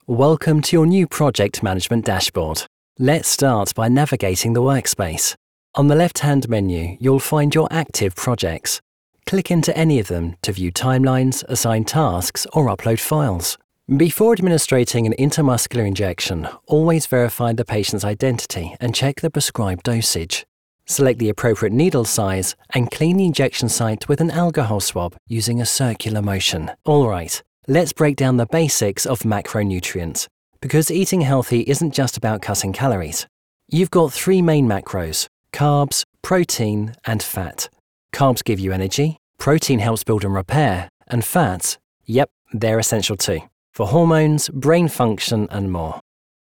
Kommerziell, Natürlich, Unverwechselbar, Freundlich, Junge, Corporate
E-learning
neutral British accent described as having a fresh, clear, measured and self-assured tone